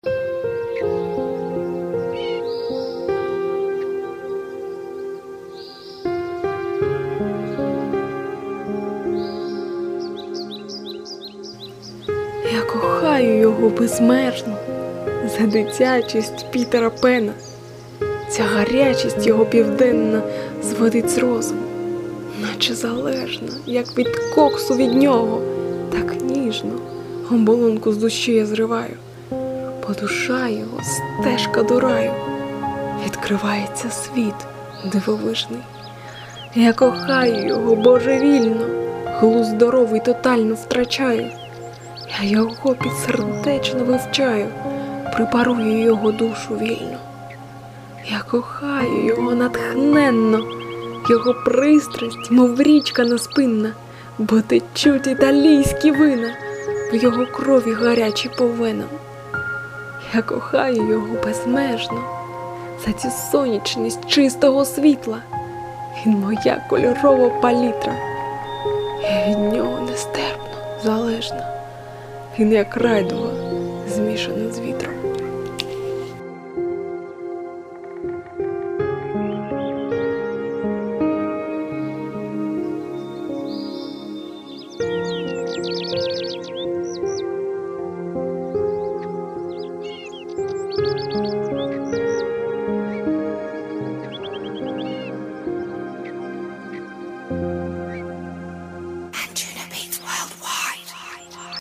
Воно Вам вдалося. І супровід, і узгодженість, і голос.
Чудова поетеса і прекрасний декламатор! 12 give_rose